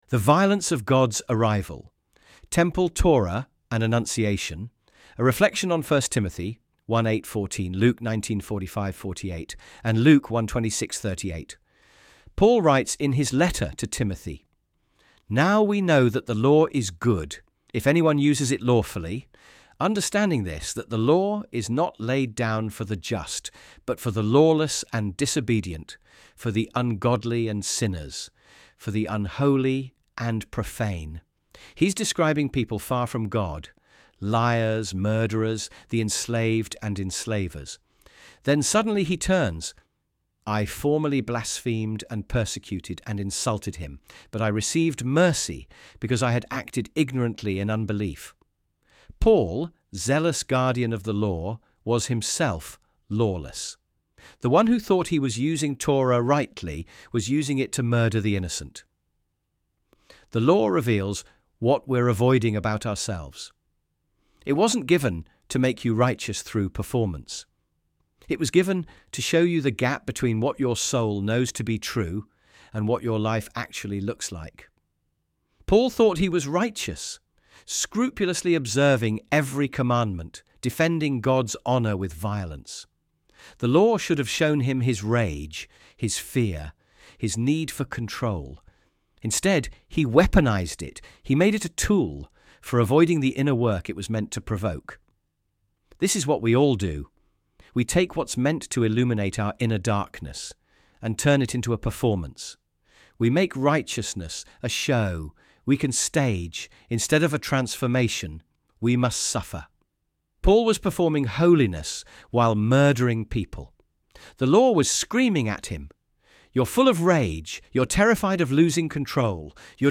audio.texttospeech-15.mp3